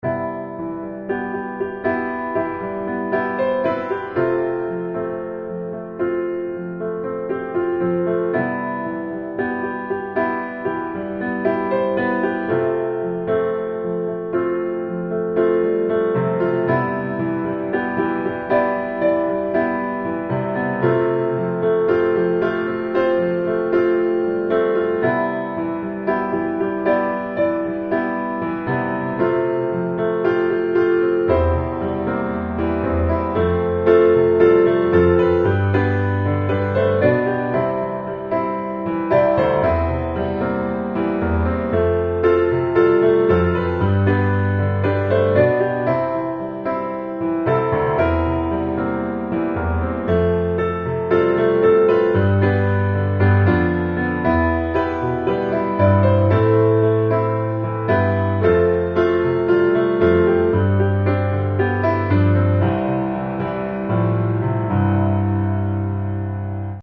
C大調